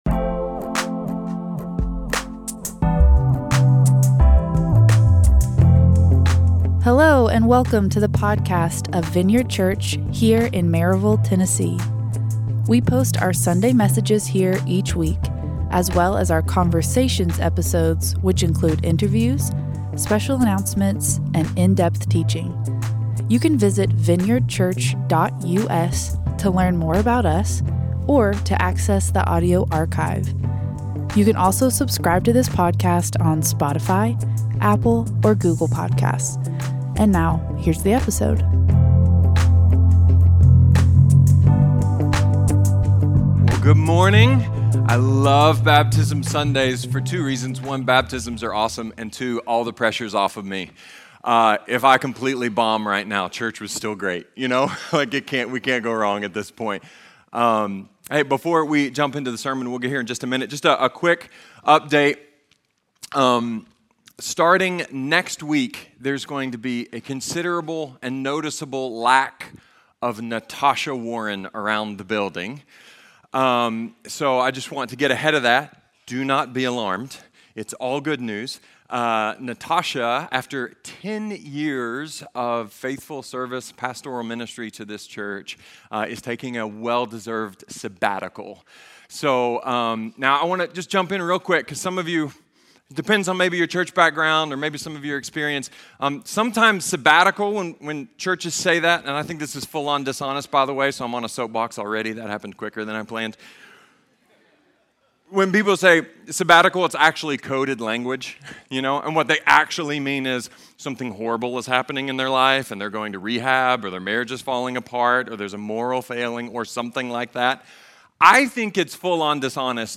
A message from the series "How to Pray."